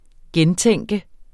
Udtale [ -ˌtεŋˀgə ]